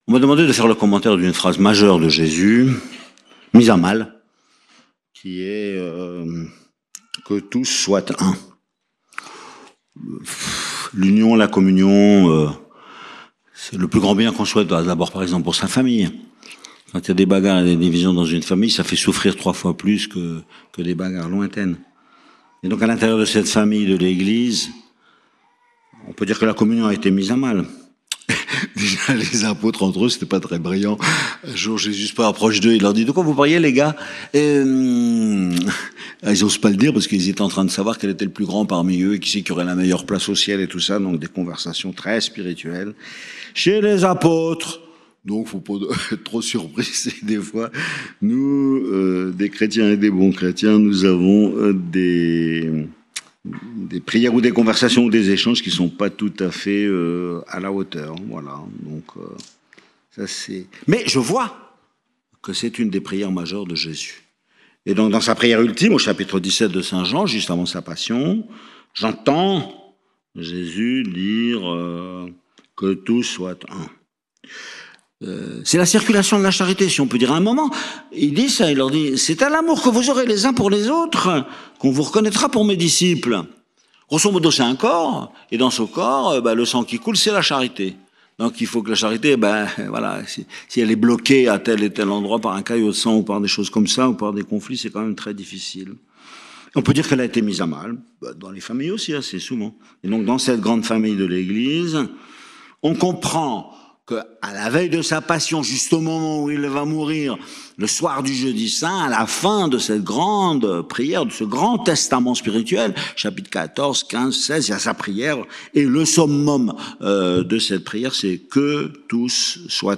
Notre Dame du Laus - Festival Marial - Mgr Barbarin